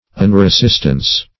Search Result for " unresistance" : The Collaborative International Dictionary of English v.0.48: Unresistance \Un`re*sist"ance\, n. Nonresistance; passive submission; irresistance.